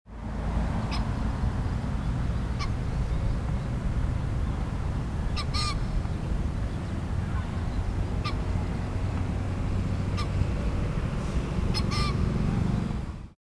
It sounded like a timid Rooster with a hesitating call; one sharp cry, followed by another, followed by a double cry. Like, “ka! … ka! … ke-ka!”
here is an mp3 of the call.